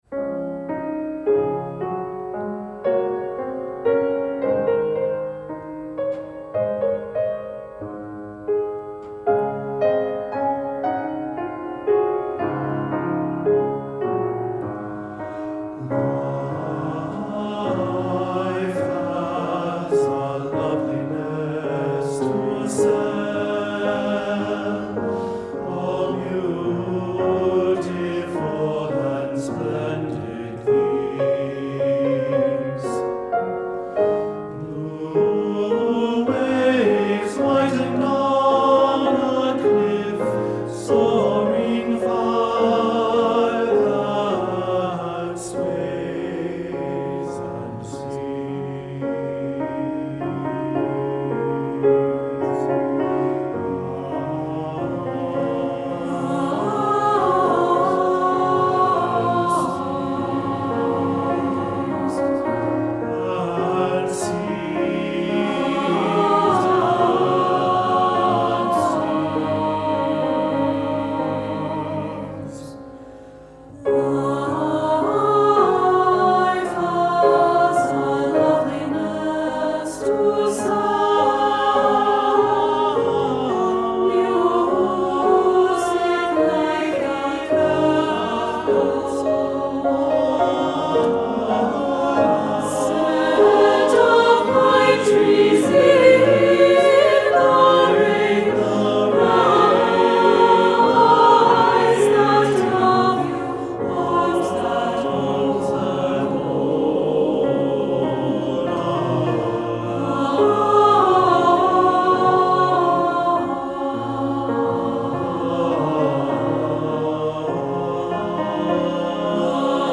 Lyrical, well-crafted, and satisfyingly developed.
for SATB div. choir & piano